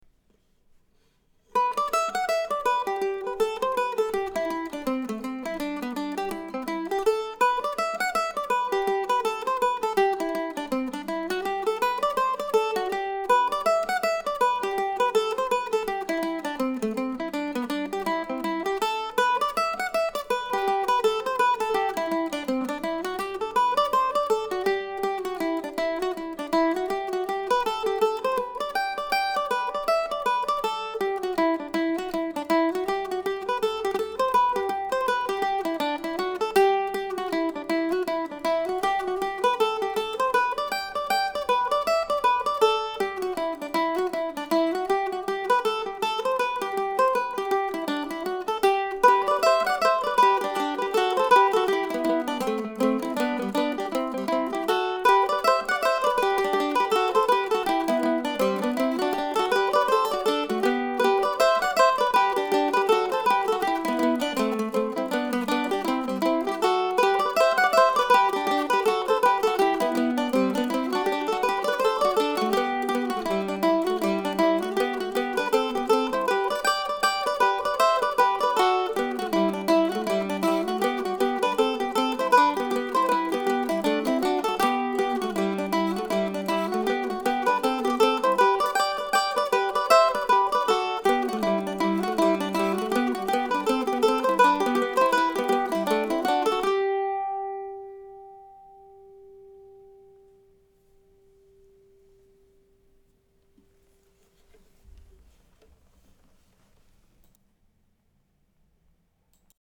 This week's tune, a slow reel or maybe a hornpipe, is titled for a place in rural Maine that probably only a few thousand people have ever encountered.